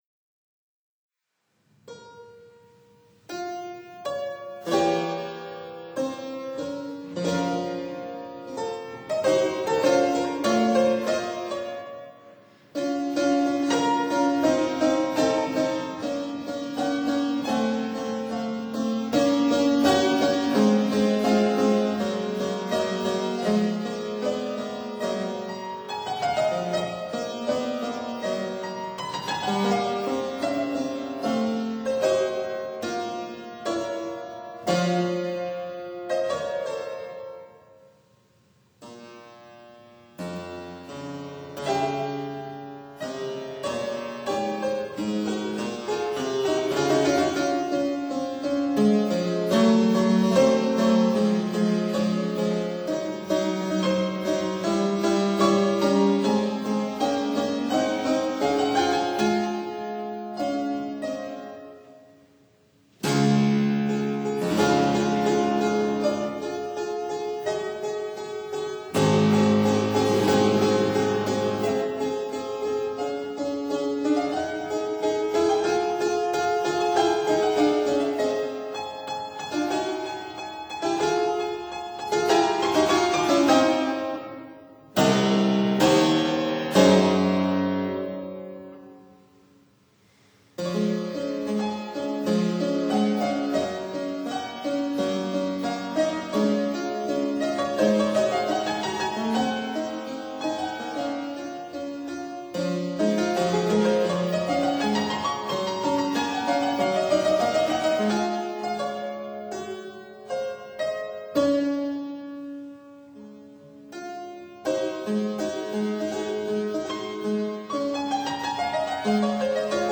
Tangent Piano & Pantalon Square Piano
(Period Instruments)